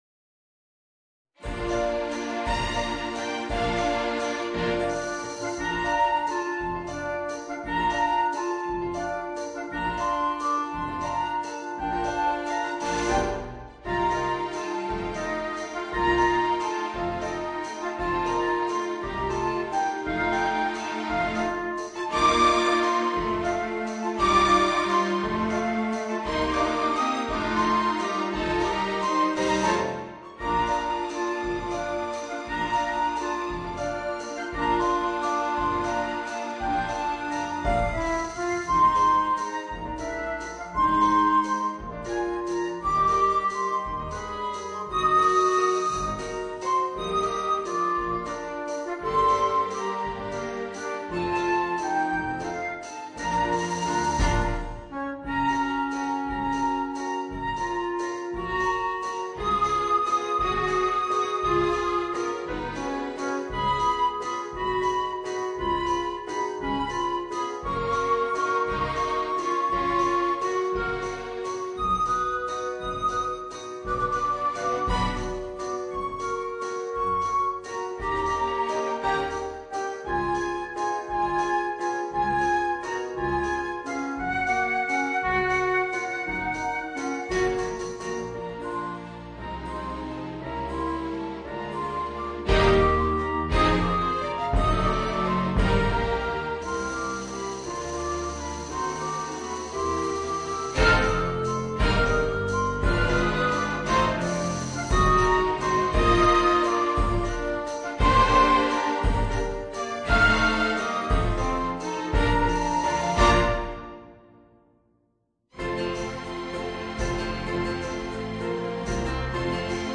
Flute, Clarinet and Rhythm Section and Strings